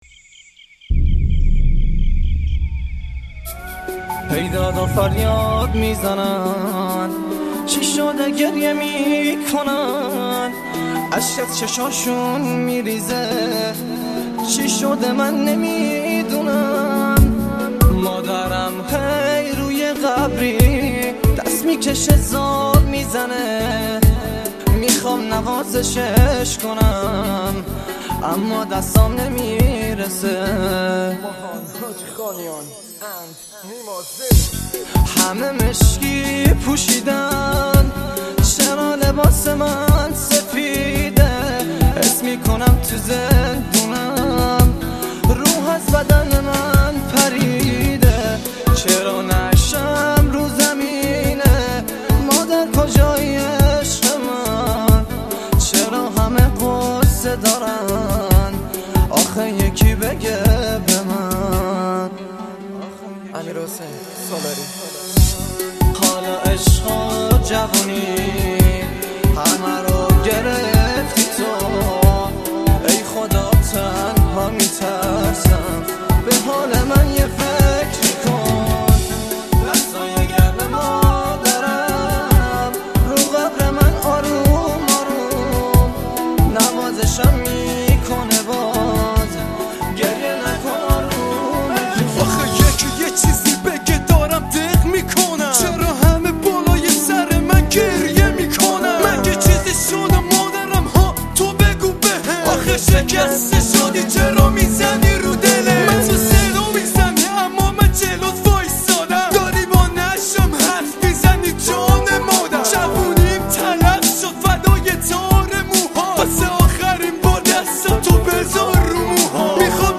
غمگین رپ